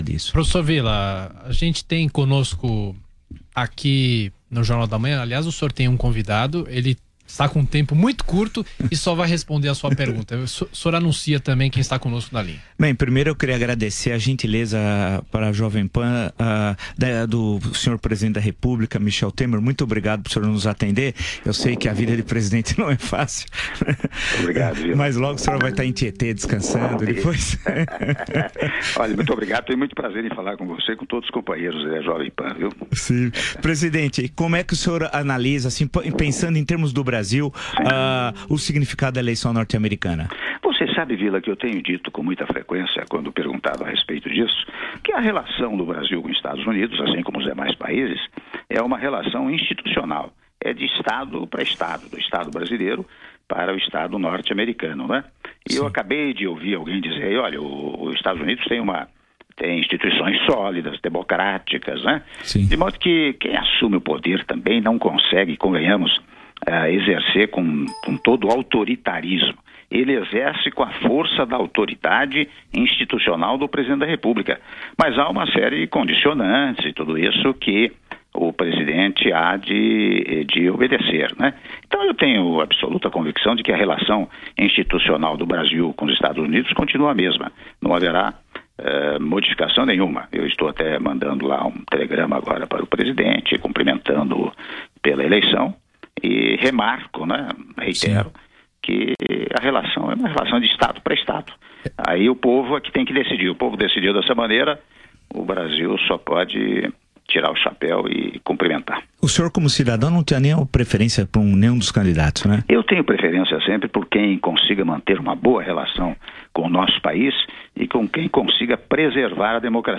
Áudio da entrevista concedida pelo presidente da República, Michel Temer, para a Rádio Jovem Pan - (03min48s) - Brasília/DF